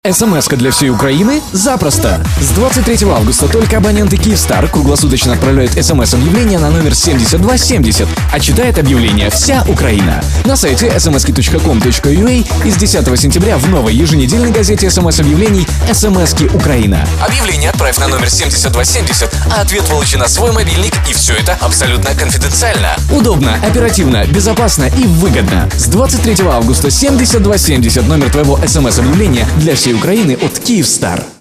Рекламный радиоролик